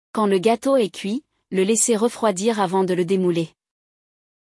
Hoje, vamos acompanhar uma conversa entre dois amigos: um deles quer preparar um bolo de aniversário, mas não conhece nenhuma receita.